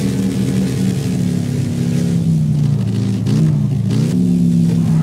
slowdown.wav